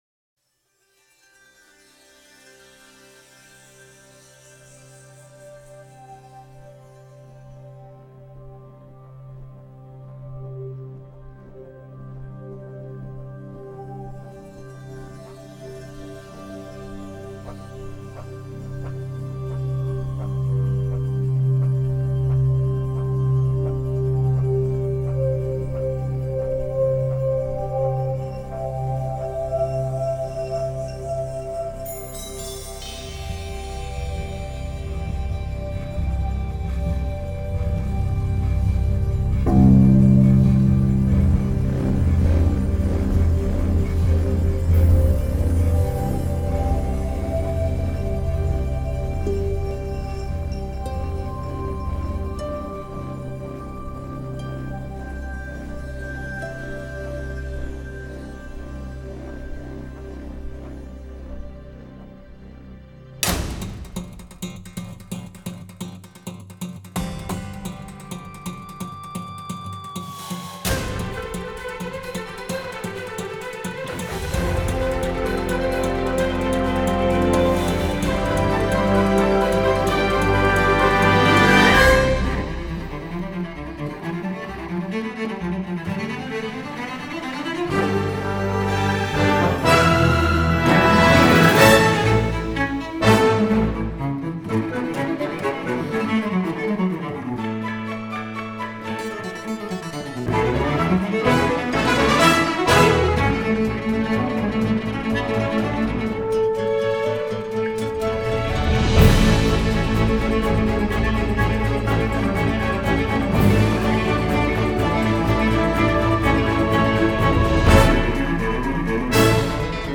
2007   Genre: Soundtrack   Artists